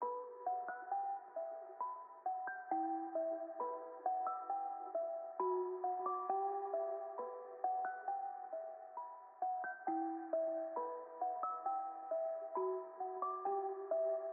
Krazy [134] Piano 2.wav